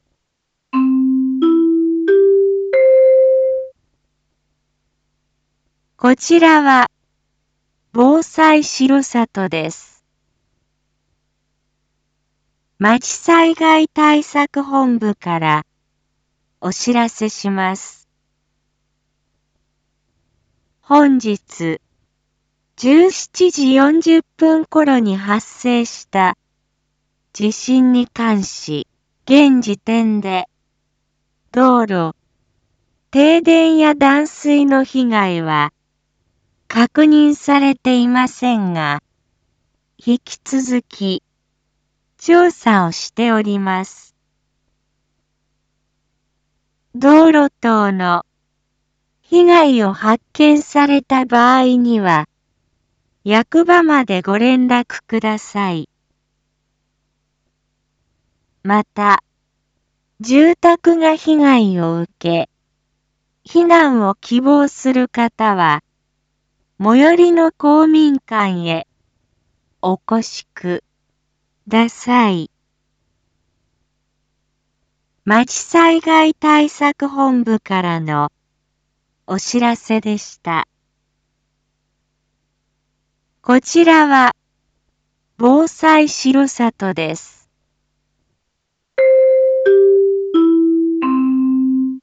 一般放送情報
Back Home 一般放送情報 音声放送 再生 一般放送情報 登録日時：2022-11-09 18:34:33 タイトル：地震について インフォメーション：こちらは防災しろさとです。